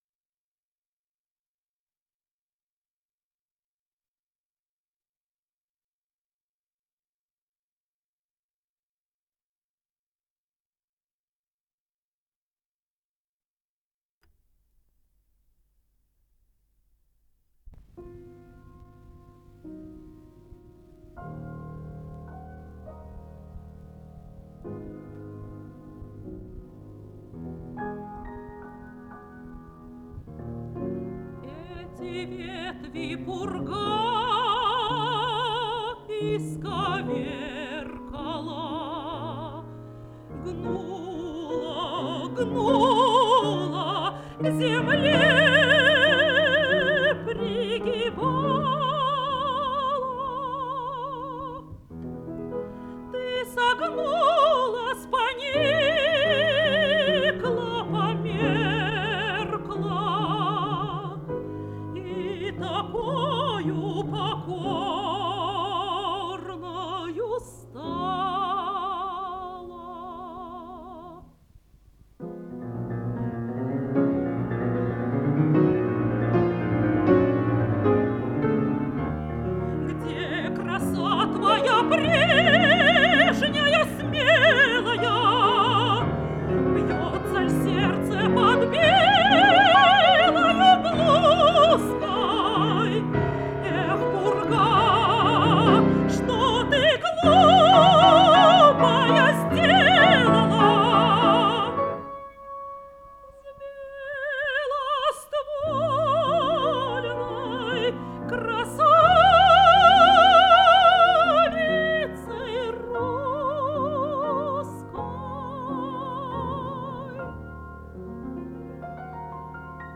пение